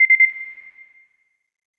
lock.wav